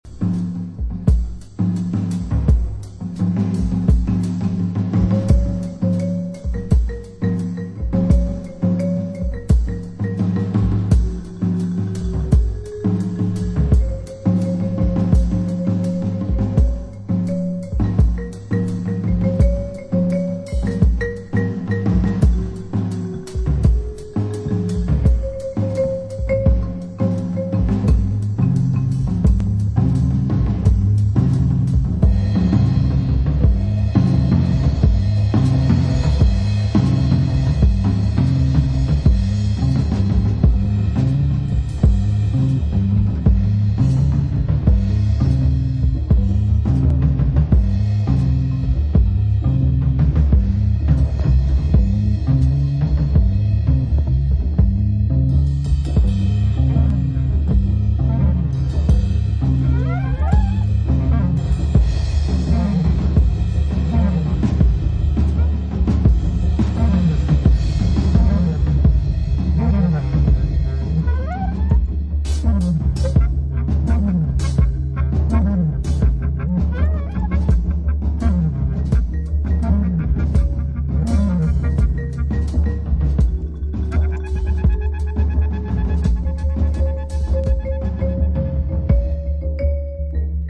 Very experimental 12''
Almost an early 80’s techno record.
Disco Electro